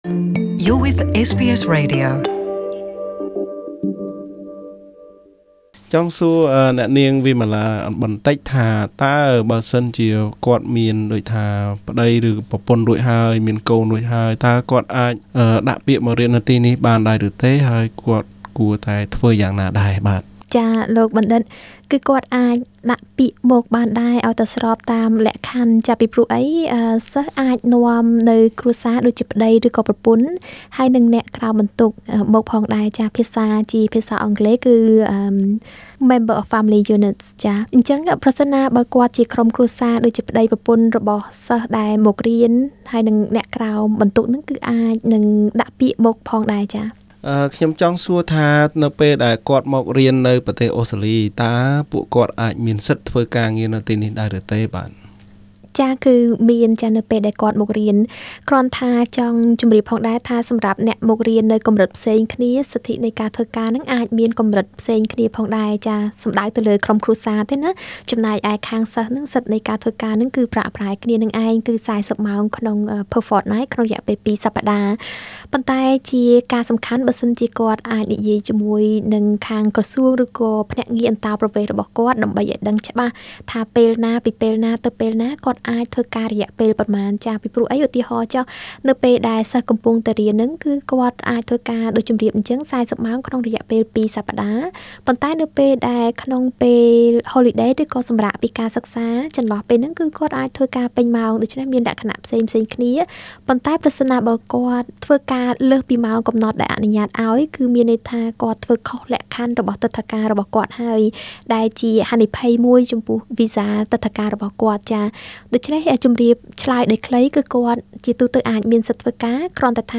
បទសម្ភាសន៍បន្តស្តីពីការដាក់ពាក្យសុំទិដ្ឋាការសិស្សមកសិក្សានៅអូស្ត្រាលី